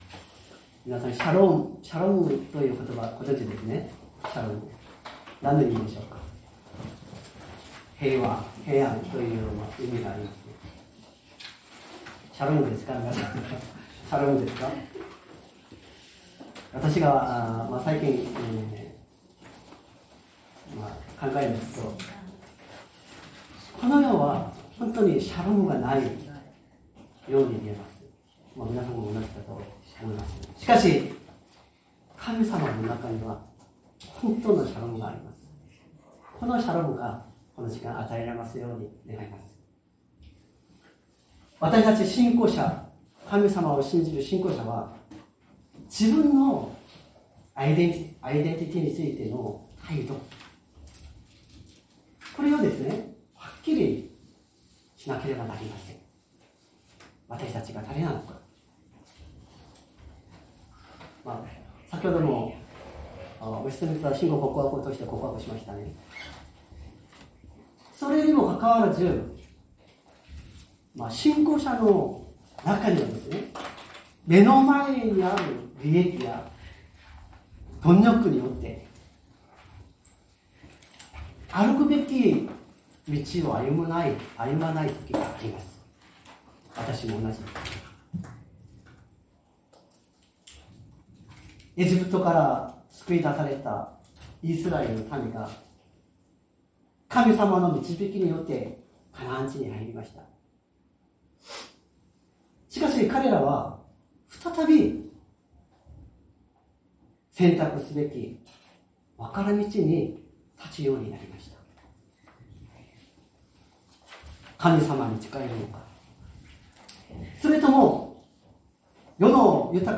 2025年05月11日朝の礼拝「わたしたちも主に仕えます」善通寺教会